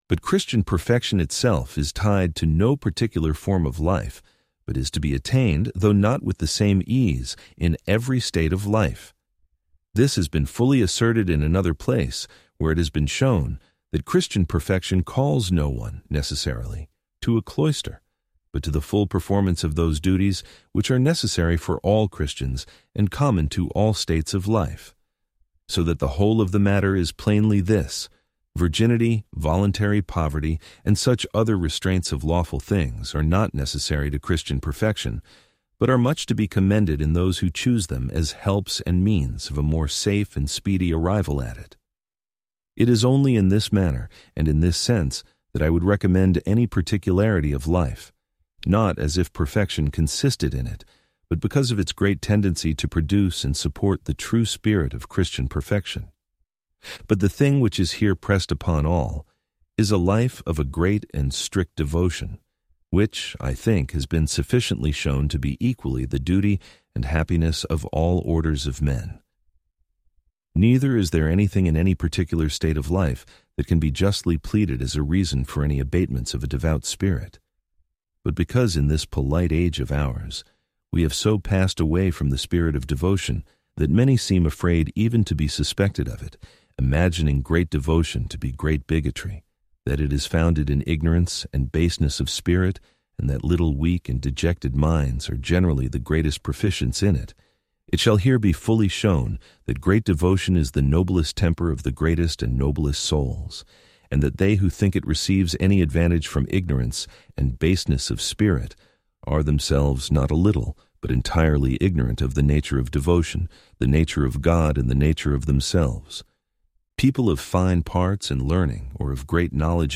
Complete Audiobook